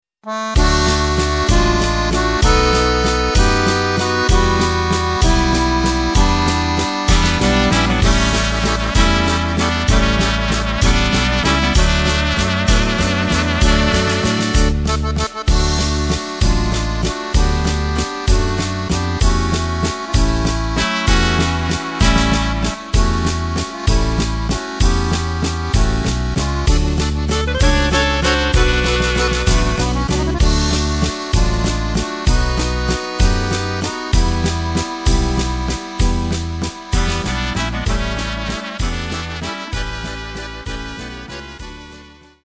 Takt:          3/4
Tempo:         193.00
Tonart:            D
Walzer aus dem Jahr 1992!
Playback mp3 Mit Drums